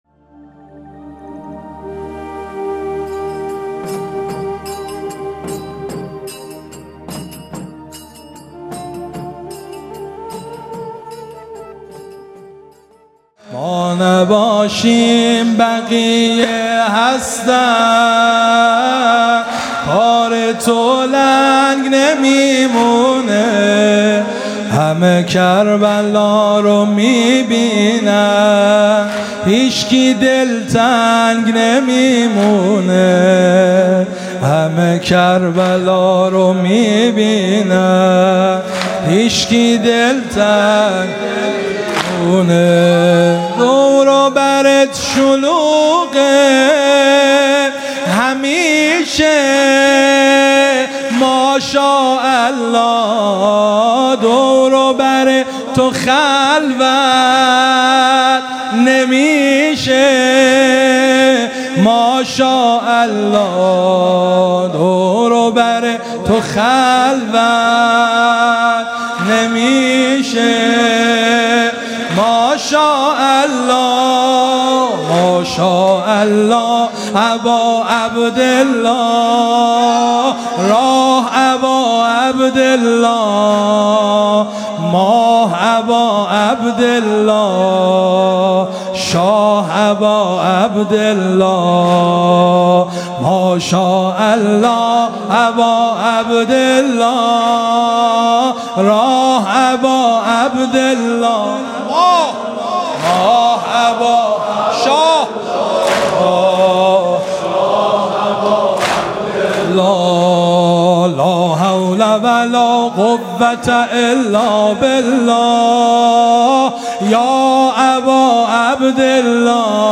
مداحی واحد
دهه اول - شب سوم محرم 1402 | هیأت انصار ولایت یزد